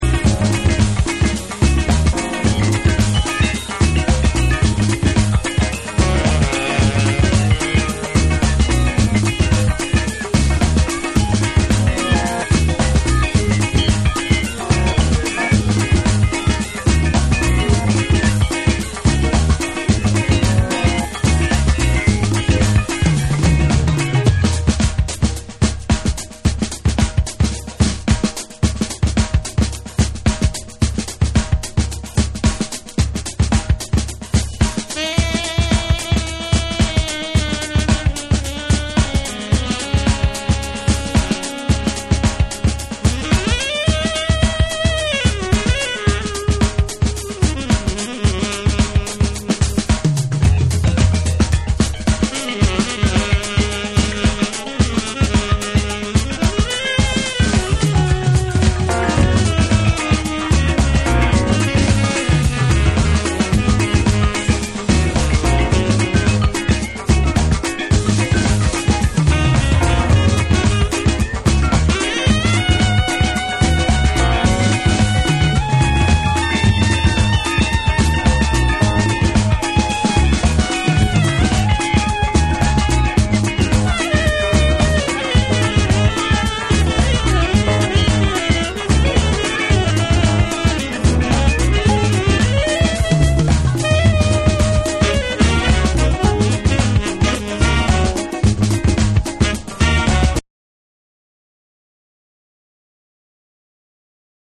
ORGANIC GROOVE / AFROBEAT